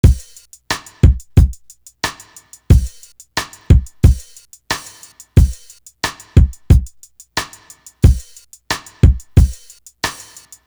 Ray Drum.wav